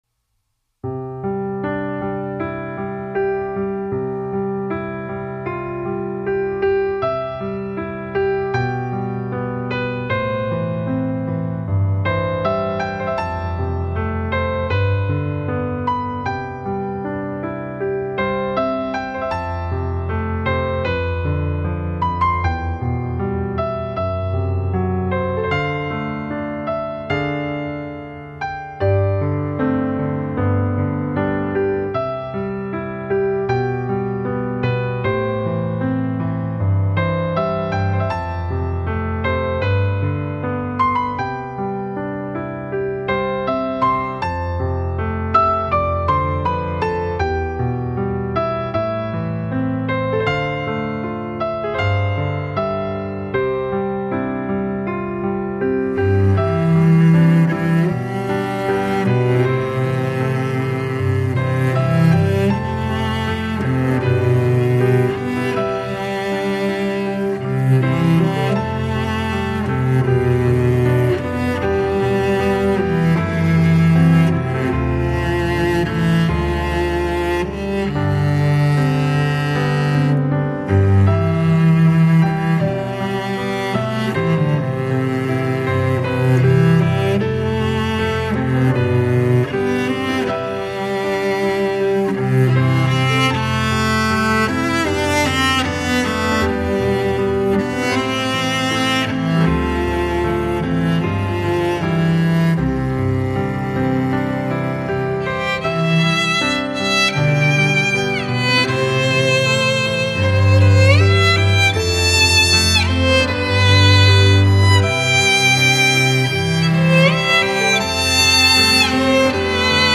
对于夜的音乐，不光光只有钢琴，加入了不同的乐器，会让整个音乐更加丰满和深情。